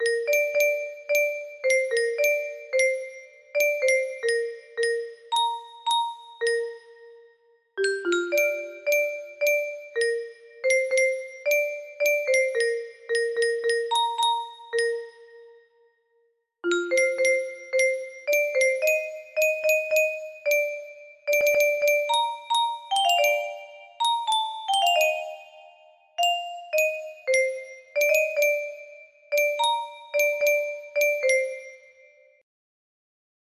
(Bb major) (original key)